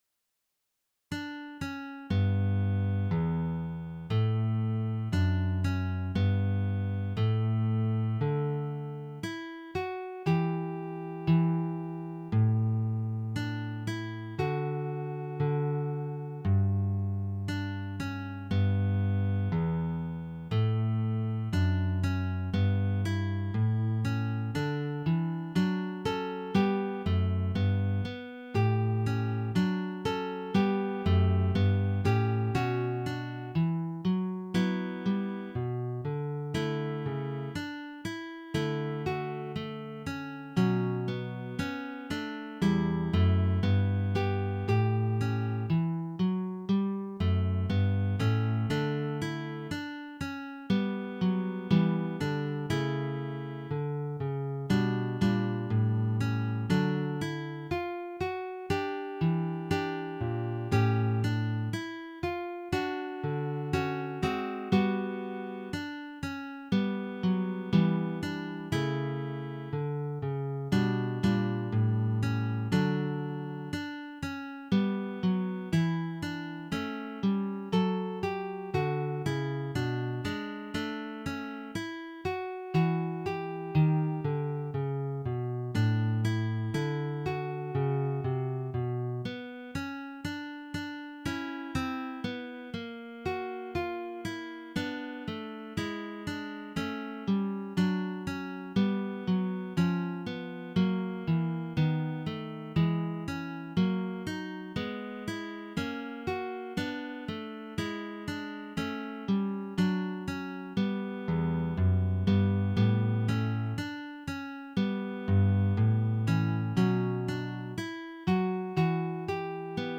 Passacaglia
Neue Musik
Sololiteratur
Gitarre (1)